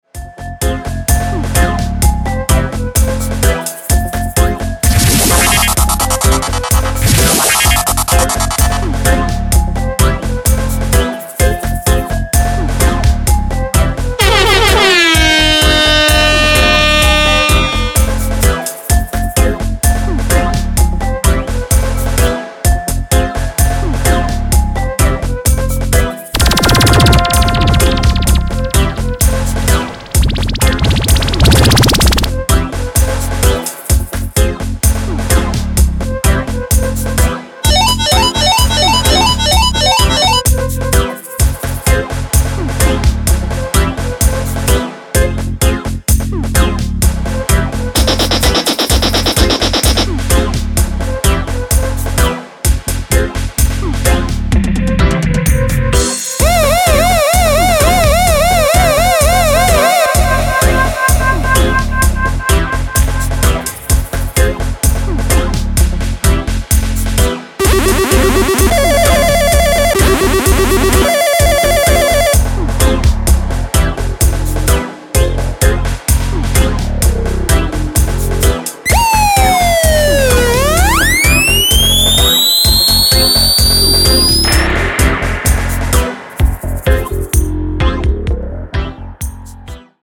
Sound Effects Unit
Many new Effects along with traditional siren
effects, horns, tone's, pulses, beep's etc.
( No Echo delay or other processing applied - Just this unit and the music playing )